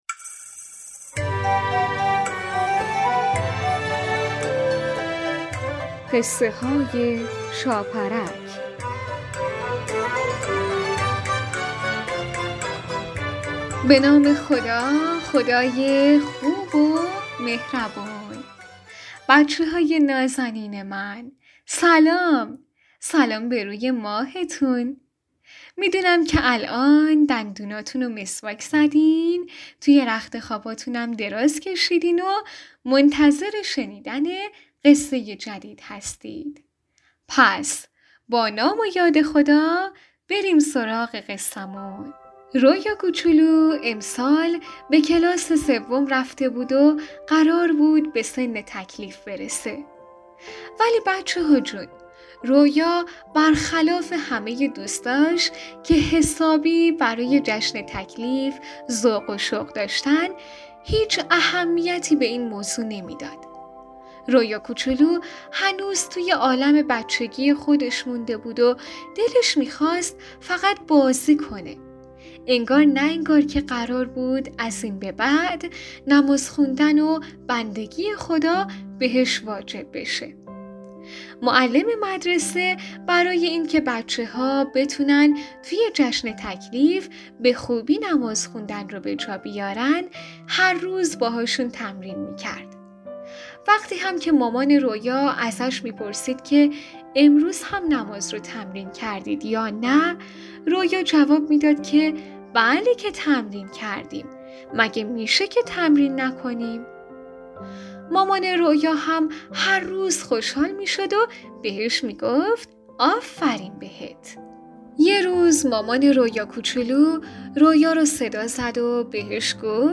قسمت پنجاه و دوم برنامه رادیویی قصه های شاپرک با نام دروغ بزرگ، قصه کودکانه نمازی مربوط به رویا کوچولو که دوست نداشت نماز بخونه...